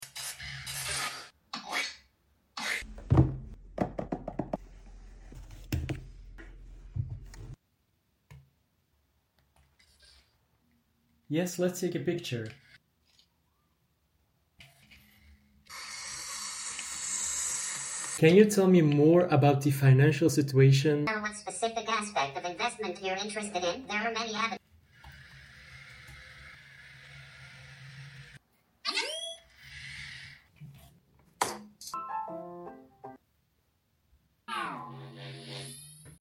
ASMR Study Session Ft. LOOI🤖 Sound Effects Free Download